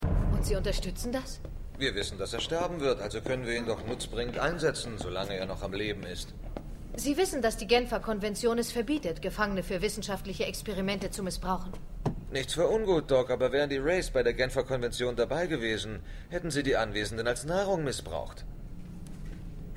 Datei) 309 KB {{Information |Beschreibung = Sheppard diskutiert mit Weir über die Nutzung des Wraith Steve zu Testzwecken |Quelle = SGA 1x07 |Urheber = MGM |Datum = 31.08.2010 |Genehmigung = MGM |Andere Versionen = keine |Anmerkungen = }} 1
Dialog_2_SGA_1x07.mp3